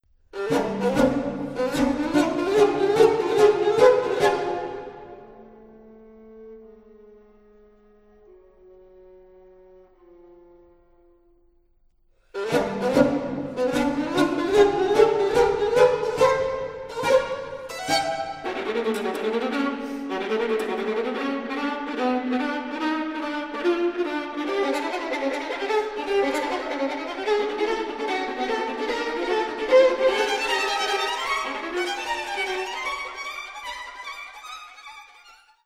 Sextet for Piano and Winds
His music tends to be direct and tuneful, merging classical influences with the popular music of his time.
In the outer sections of the first movement, for example, campy band music alternates with harsh chromaticism, while in the second movement, a lyrical oboe solo contrasts with a march.